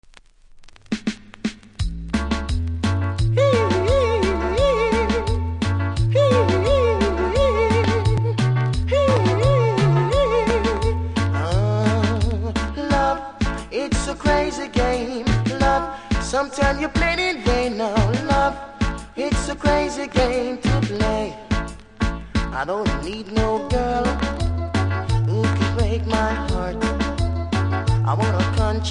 キズは多めでそれなりにノイズ感じますので試聴で確認下さい。